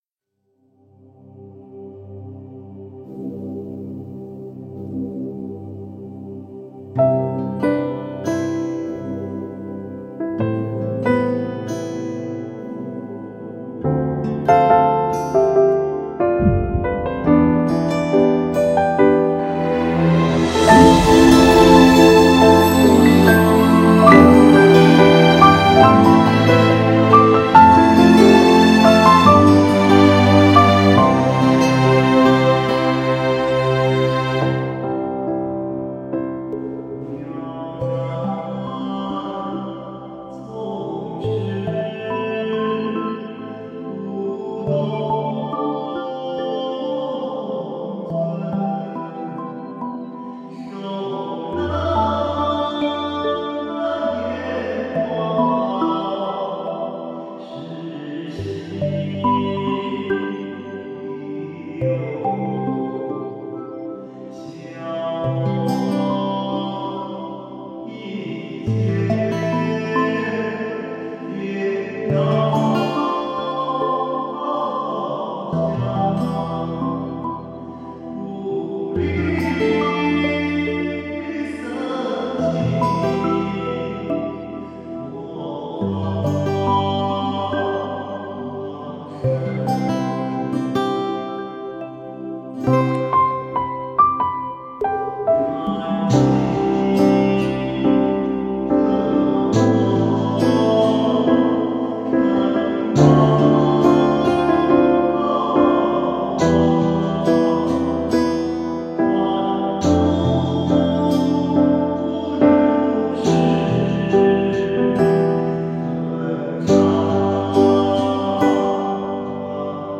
佛音 冥想 佛教音乐 返回列表 上一篇： 佛教音乐-心经--佛歌洞萧纯音乐 菩萨 下一篇： 财神咒(音乐